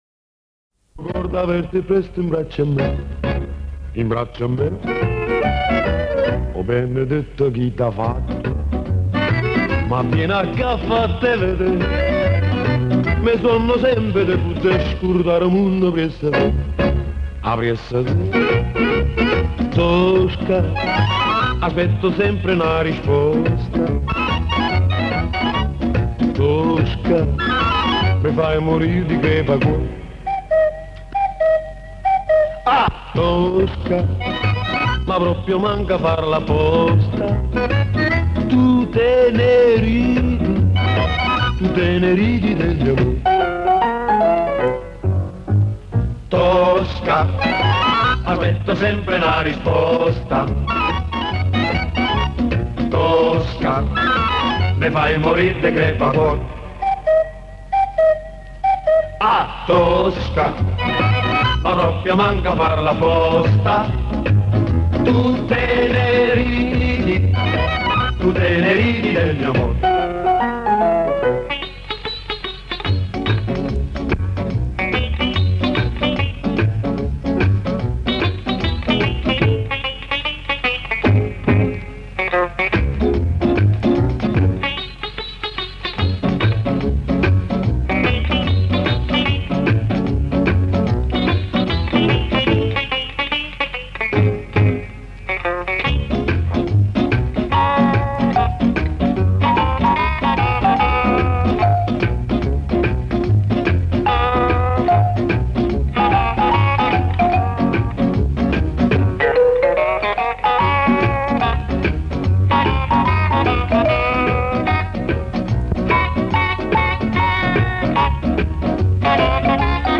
У меня нашлась старая кассета и на ней записана музыка,но я не знаю кто исполнители хотел бы узнать и записать их альбомы.